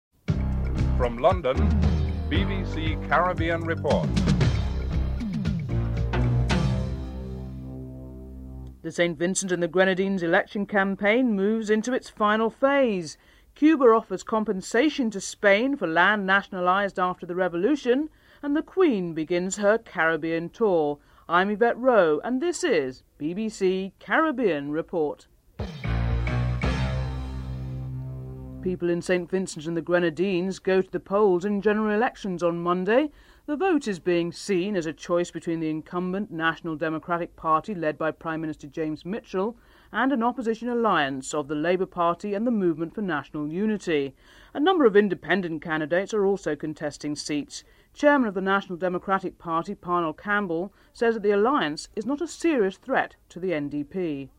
1. Headlines (00:00-00:27)
8. Theme music (14:59-15:04)